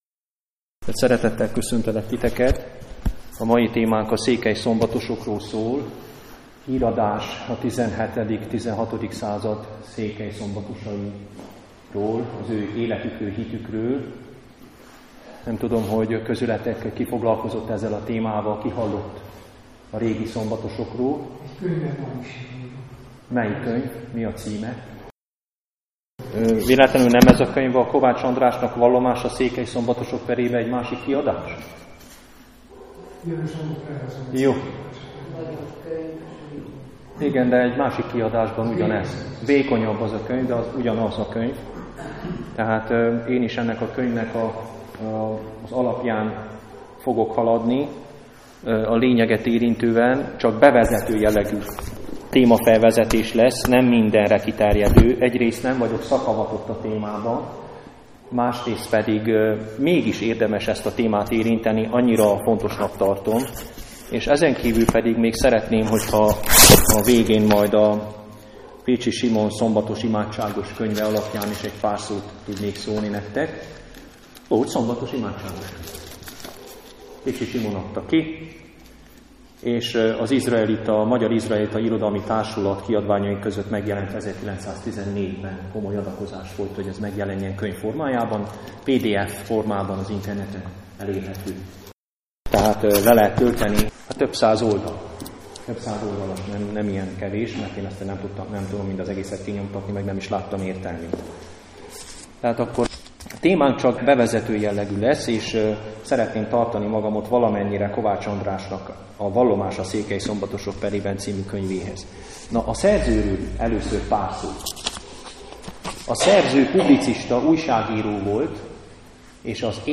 Az egykori székely szombatosokról a bevezető jellegű előadást itt érheted el!